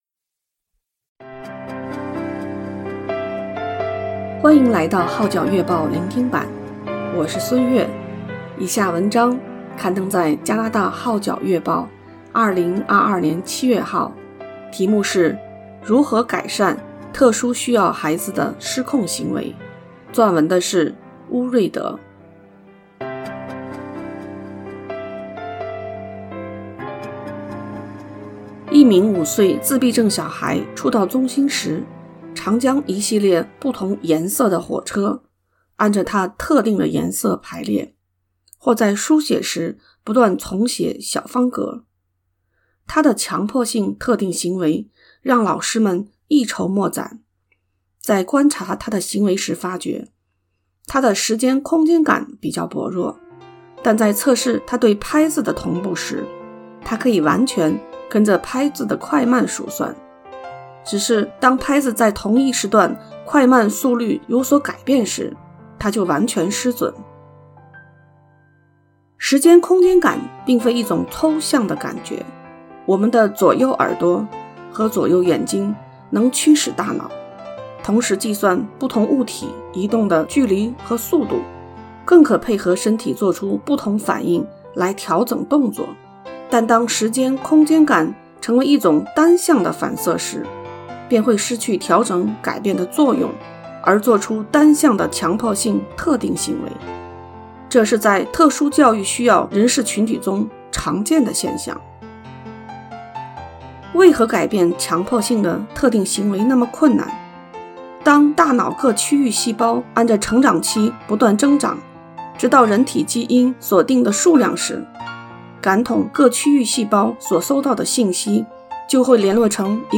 如何改善特殊需要孩子的失控行為？ 下載粵語MP3檔案